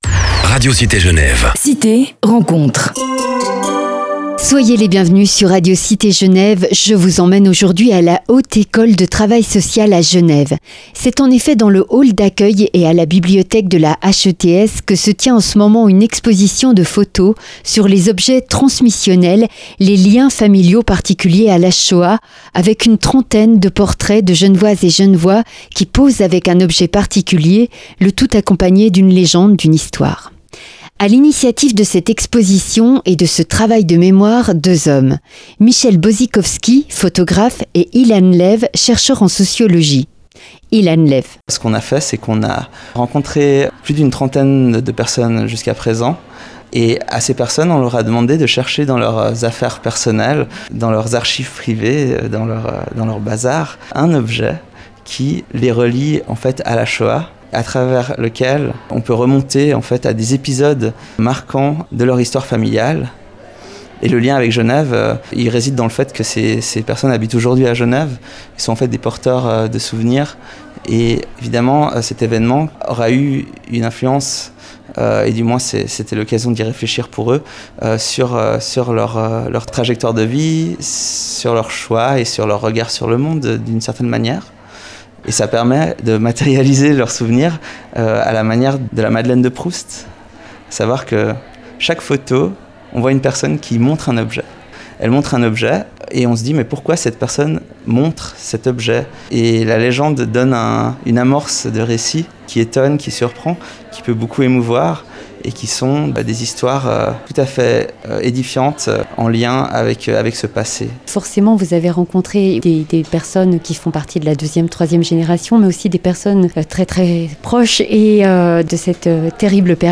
Entretien
Radio-Objets-Transmissionnels.wav